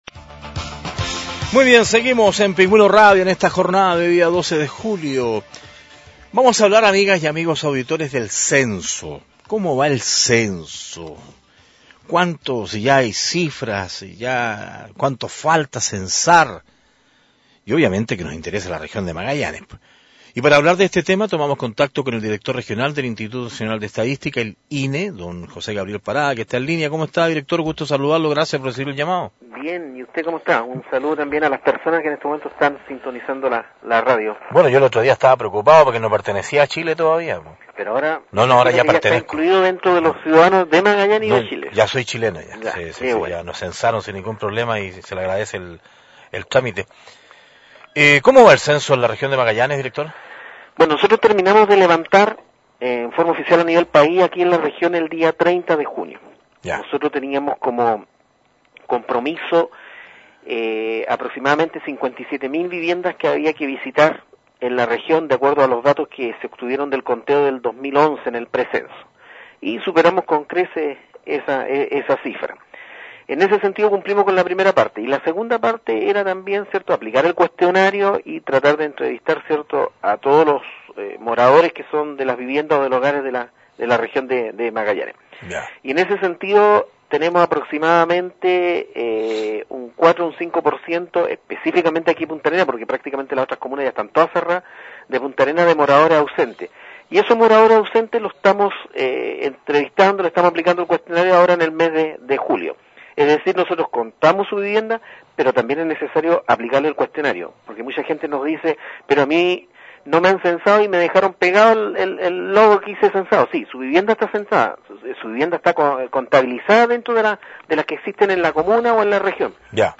Entrevistas de Pingüino Radio - Diario El Pingüino - Punta Arenas, Chile
Vicente Karelovic, concejal